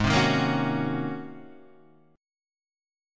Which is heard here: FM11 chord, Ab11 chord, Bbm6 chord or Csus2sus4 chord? Ab11 chord